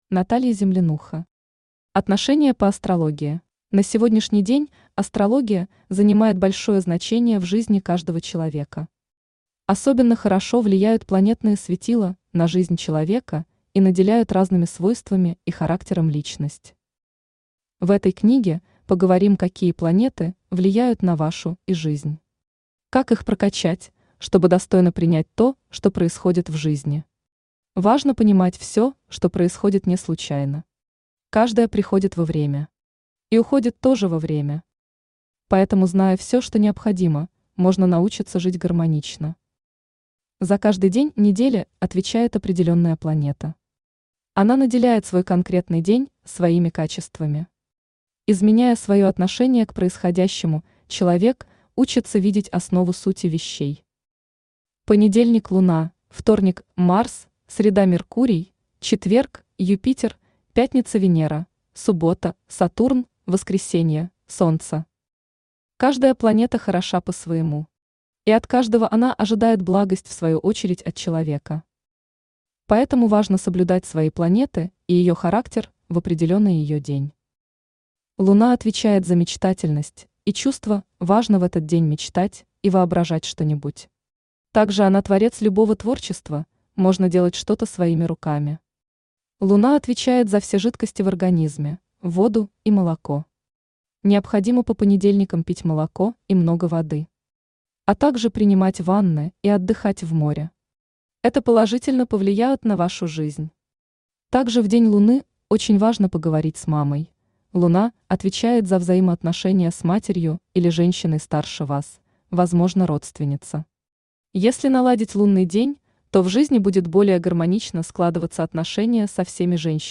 Аудиокнига Отношения по астрологии | Библиотека аудиокниг
Aудиокнига Отношения по астрологии Автор Наталья Землянуха Читает аудиокнигу Авточтец ЛитРес.